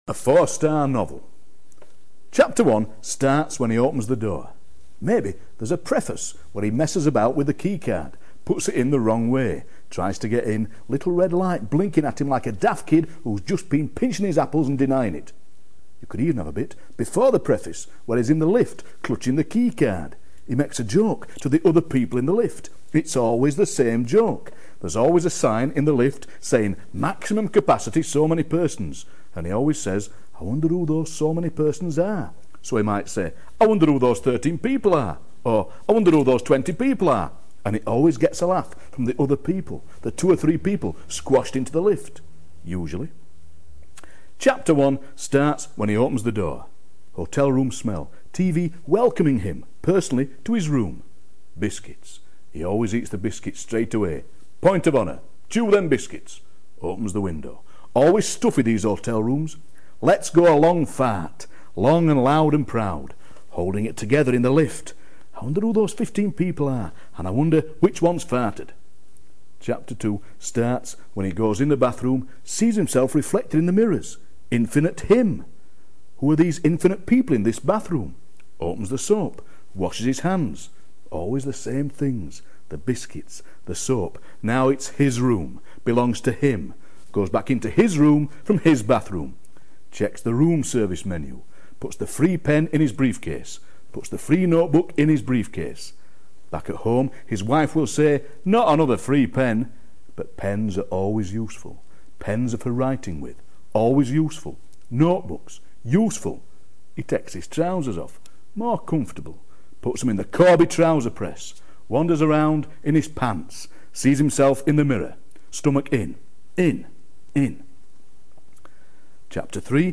Listen to Ian read 'A Four Story Novel'